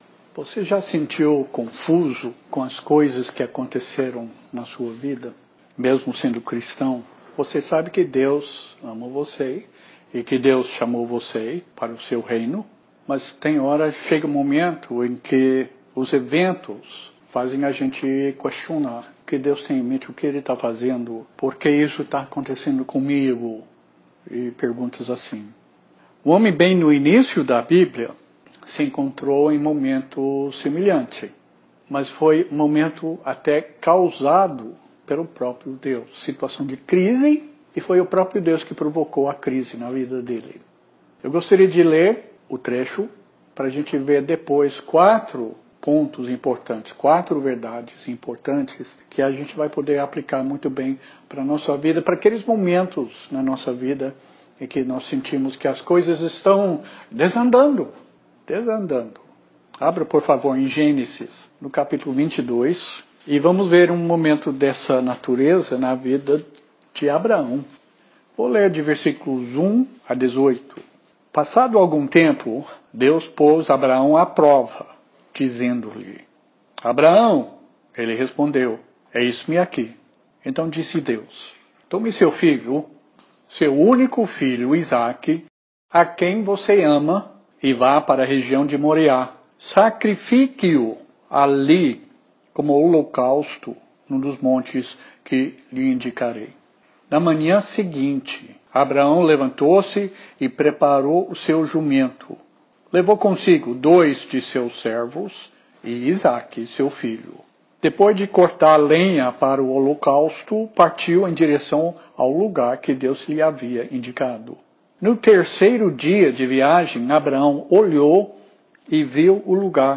Ouça o áudio de 19 minutos, editado, da mensagem do dia 8 de dezembro, no Urbanova.